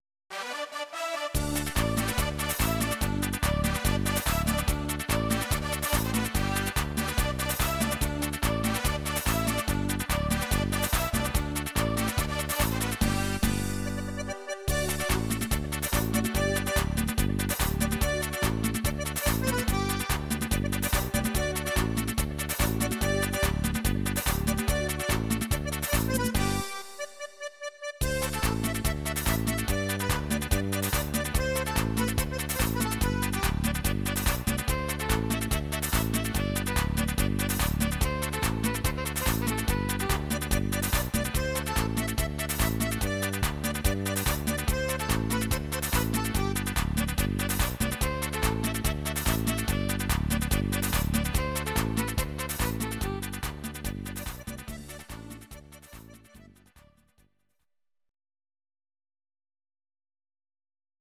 Audio Recordings based on Midi-files
Ital/French/Span